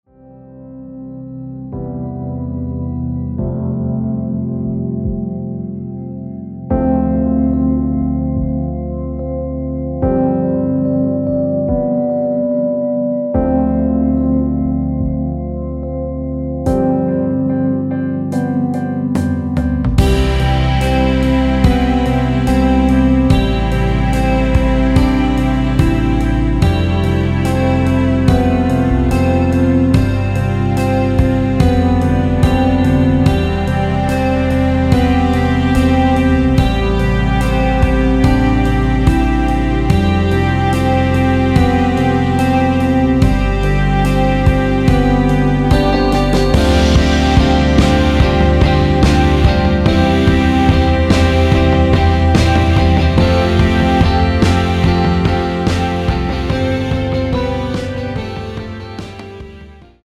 원곡 6분1초에서 4분 41초로 짧게 편곡된 MR입니다.
원키에서(-2)내린 (1절앞+후렴)으로 진행되는 MR입니다.
앞부분30초, 뒷부분30초씩 편집해서 올려 드리고 있습니다.
중간에 음이 끈어지고 다시 나오는 이유는